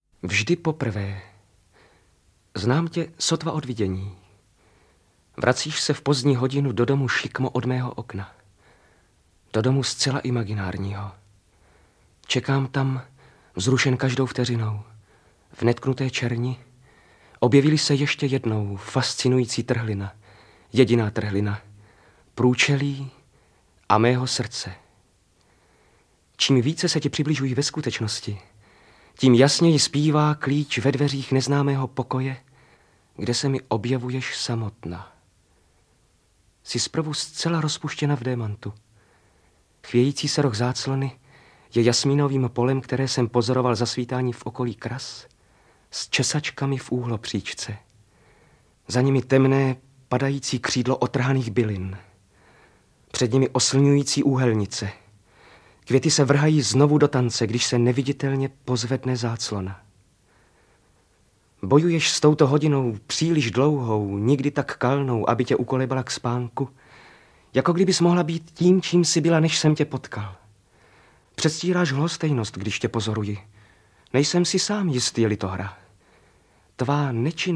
Interpreti:  Jaroslav Kepka, Luděk Munzar